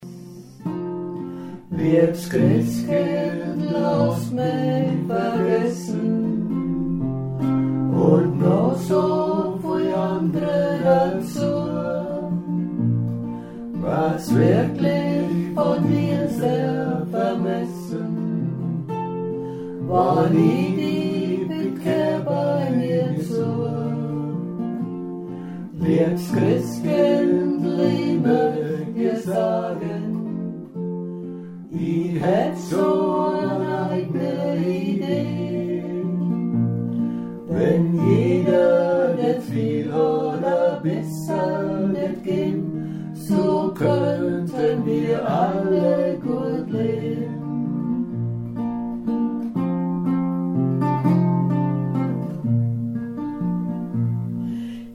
Ach ja, ich zupfe dazu die Gitarre.